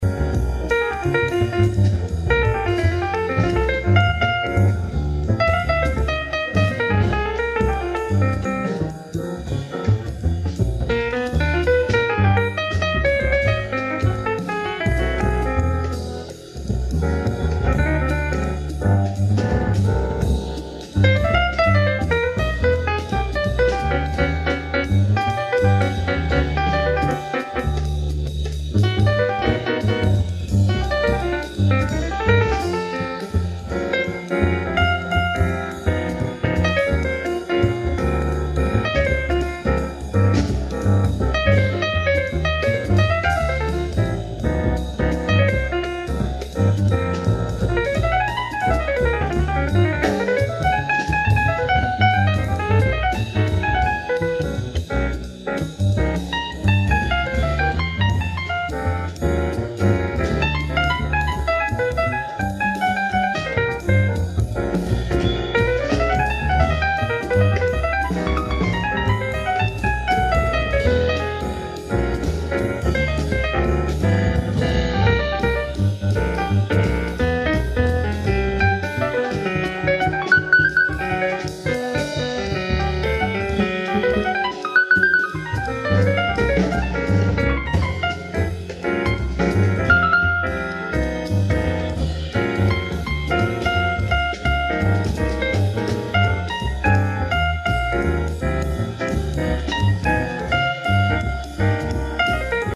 ライブ・アット・ジャズミデルハイム、アントワープ、ベルギー 08/12/1999
※試聴用に実際より音質を落としています。